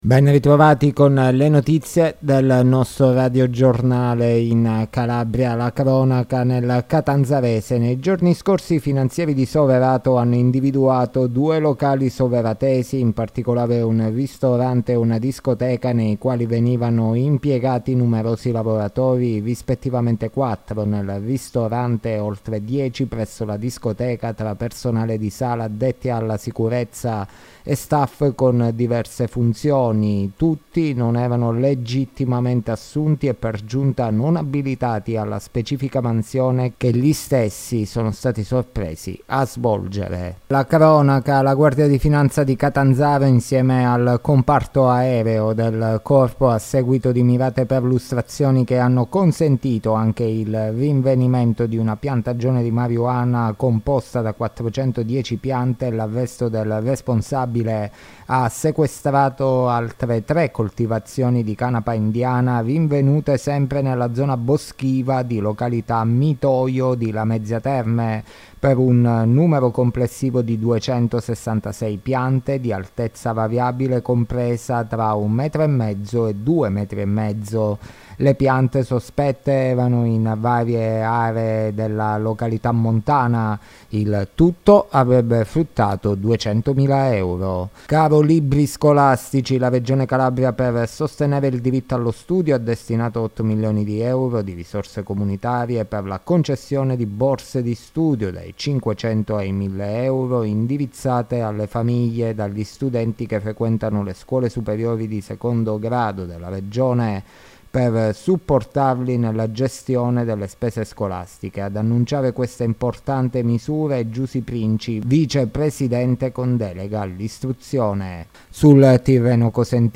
Le notizie del giorno di Mercoledì 06 Settembre 2023